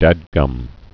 (dădgŭm)